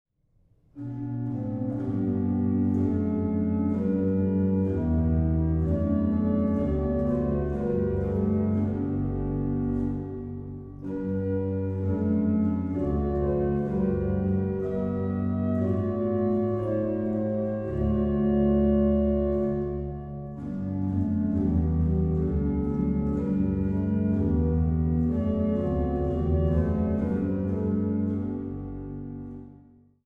Buchholz-Orgel Nikolaikirche Stralsund